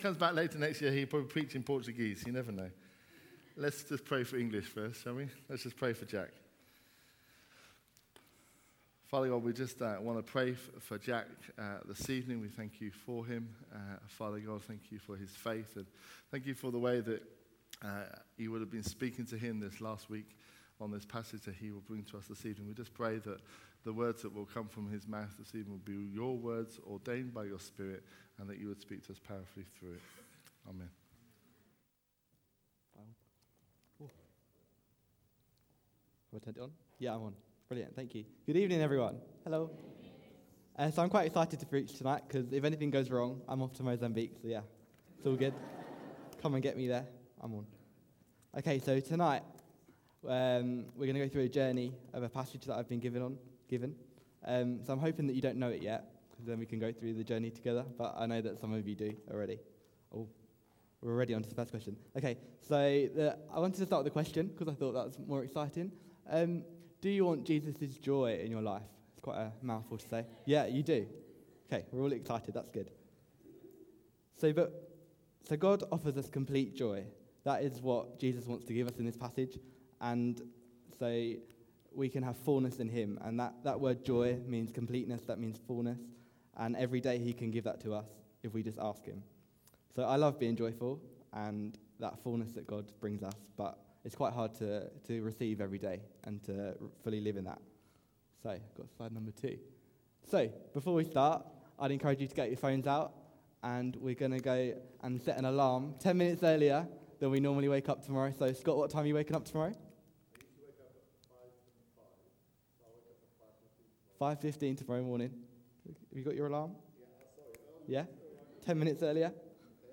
A message from the series "Jesus said 'I AM'."